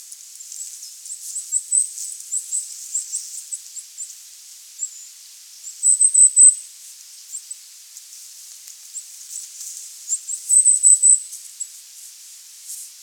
I was allowed to record a Goldcrest in the forest - but according to the children it clearly wasn't supposed to be a bird trip. 🙂
We never saw the Goldcrest -but it was clearly there: